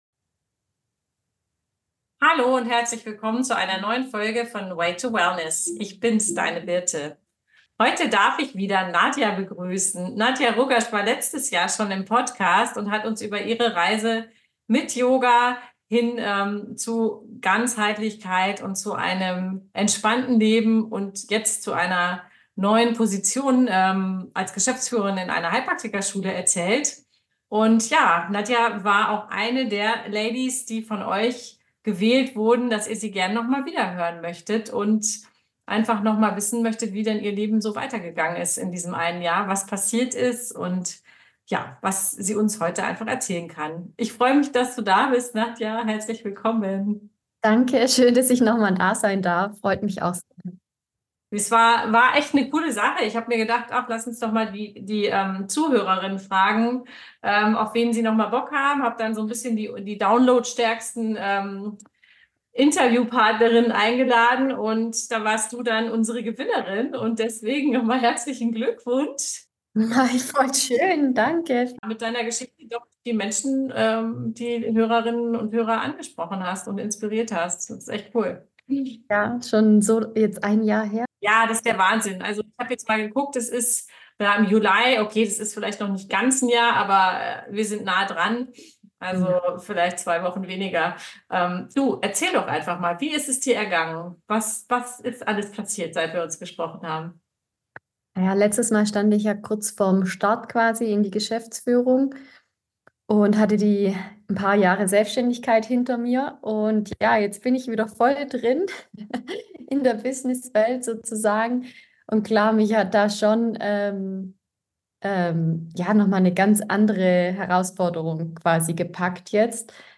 Schalte ein für ein inspirierendes Gespräch voller persönlichem Wachstum, beruflicher Einblicke und ganzheitlicher Weisheit.